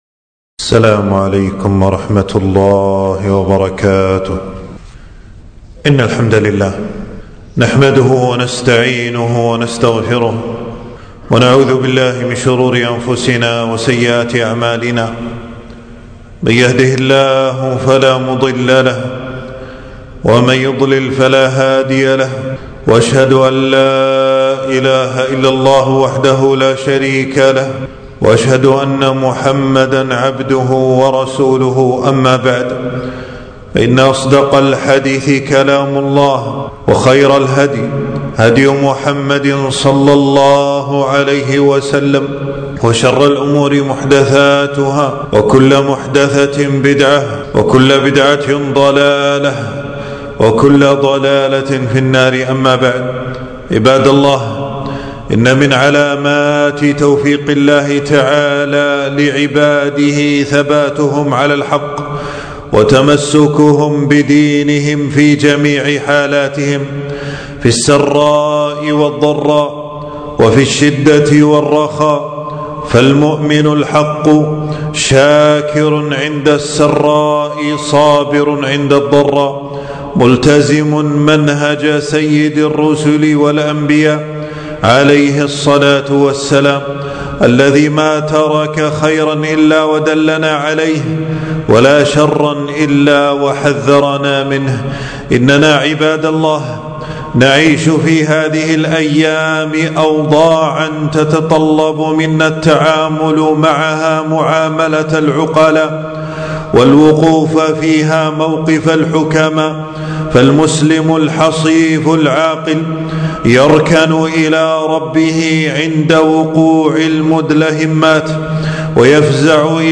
تنزيل تنزيل التفريغ خطبة بعنوان: فَاللَّهُ خَيْرٌ حَافِظًا وَهُوَ أَرْحَمُ الرَّاحِمِينَ .